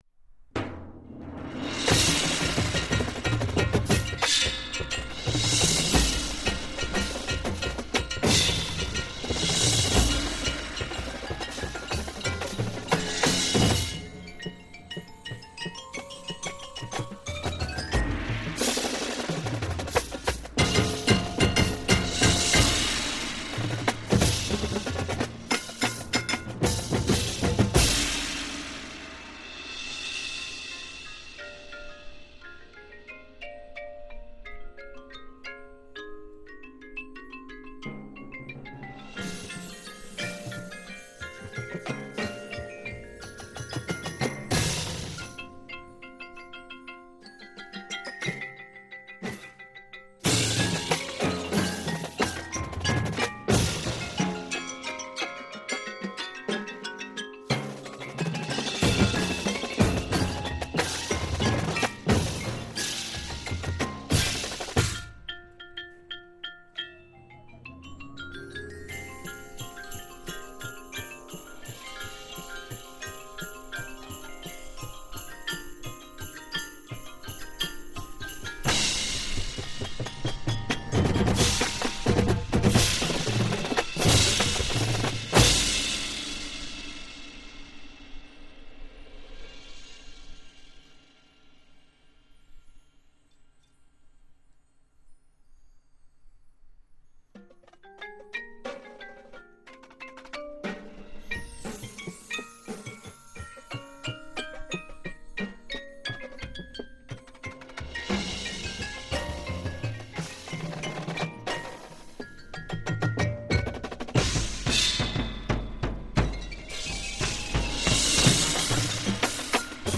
1998 Marching Band
Snares
Quints
Basses
Cymbals
Percussion Part